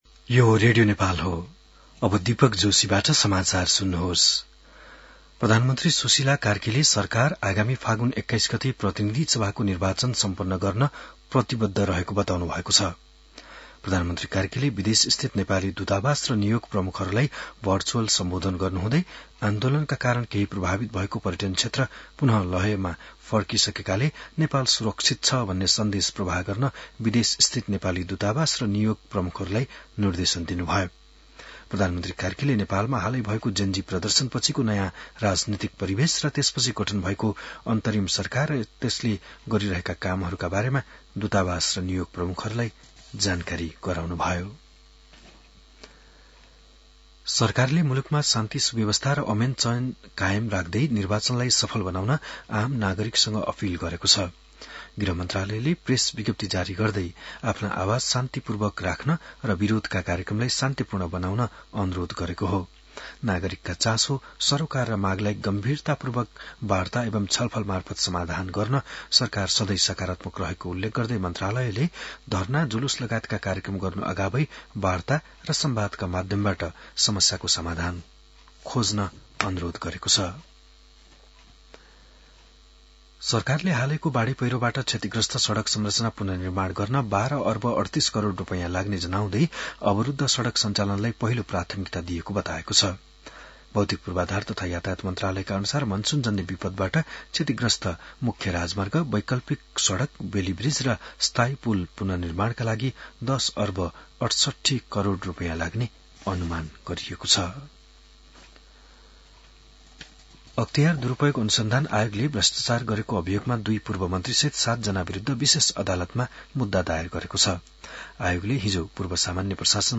बिहान १० बजेको नेपाली समाचार : २३ असोज , २०८२